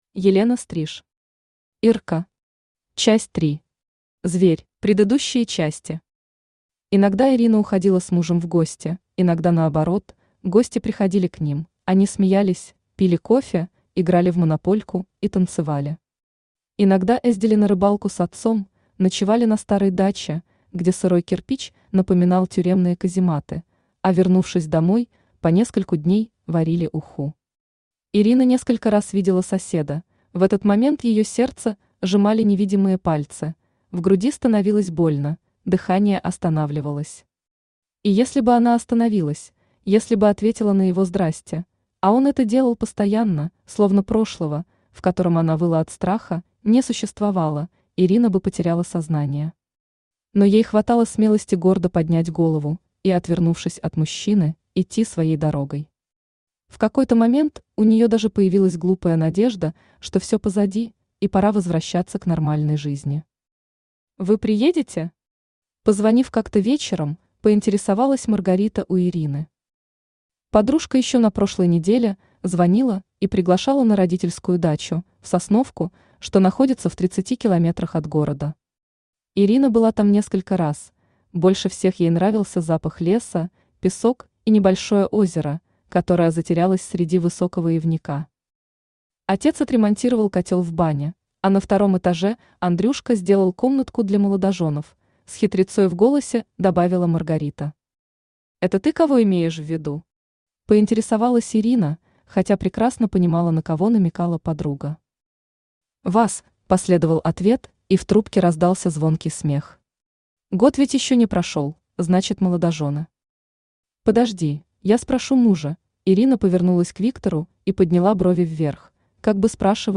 Аудиокнига Ирка. Часть 3. Зверь | Библиотека аудиокниг
Зверь Автор Елена Стриж Читает аудиокнигу Авточтец ЛитРес.